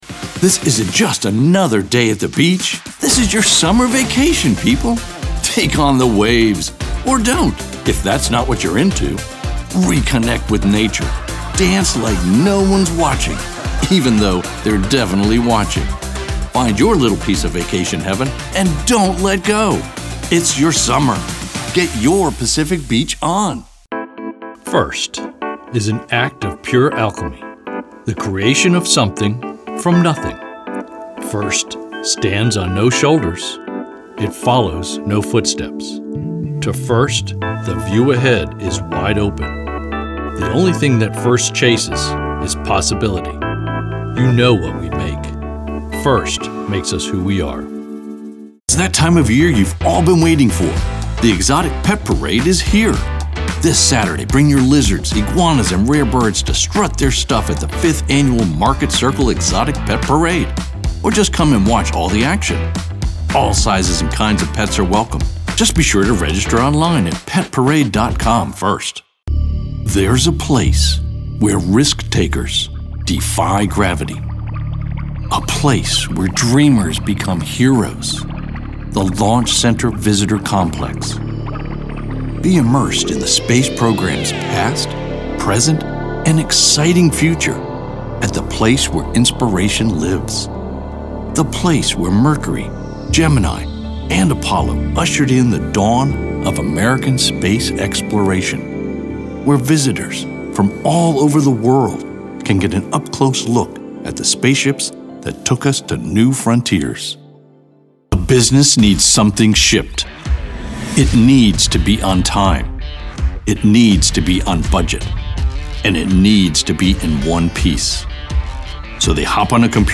announcer, anti-announcer, caring, confessional, confident, conversational, friendly, genuine, gritty, high-energy, informative, inspirational, middle-age, perky, smooth, Straight Forward, upbeat, wimp